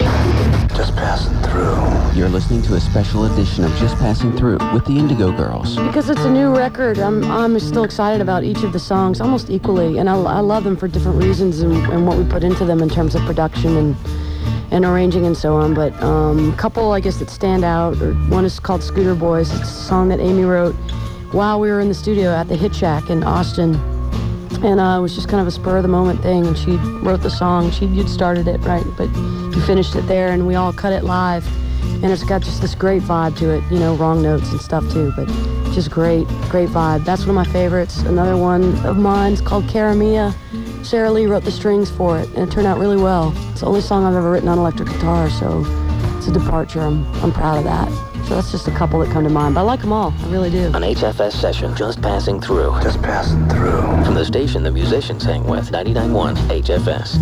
lifeblood: bootlegs: 1997-04-20: whfs radio program - rockville, maryland
04. interview (0:57)